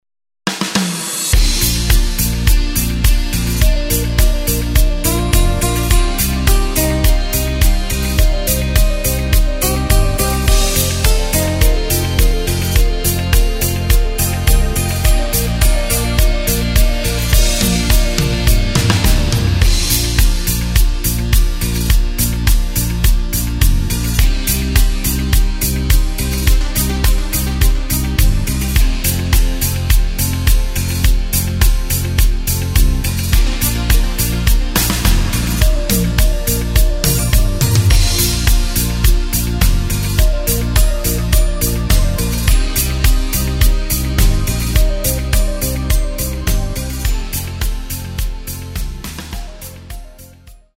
Takt:          4/4
Tempo:         105.00
Tonart:            Bb
Schlager aus dem Jahr 2011!
Playback mp3 Mit Drums